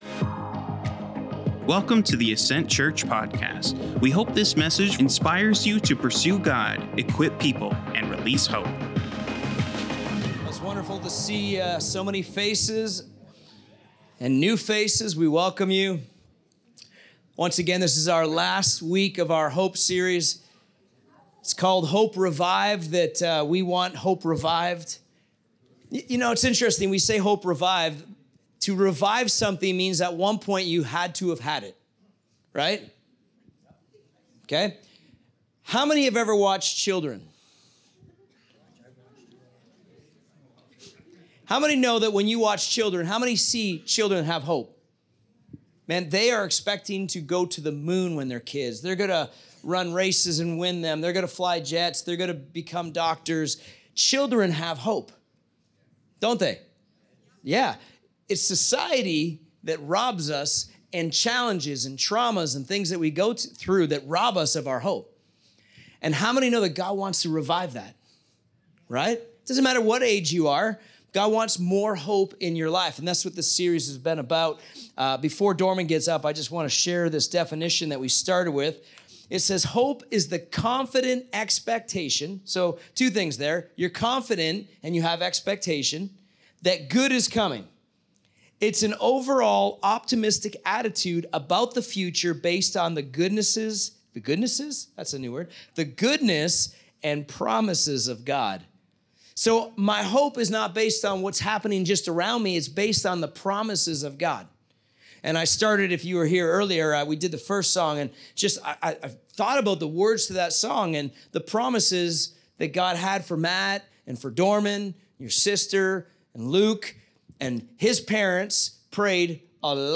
Weekly Sermon